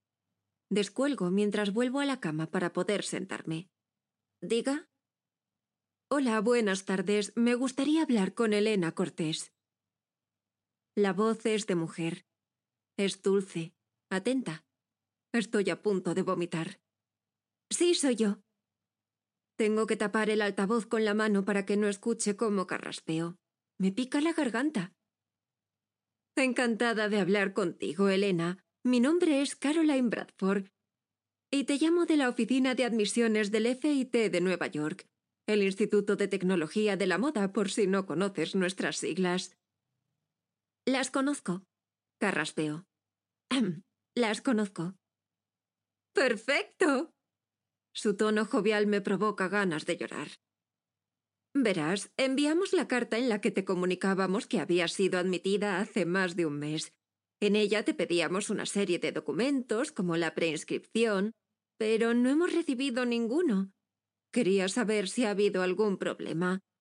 Mi voz se adapta al ritmo y estilo de cada historia, creando una narrativa cautivadora.
Fragmentos de algunas narraciones de libros: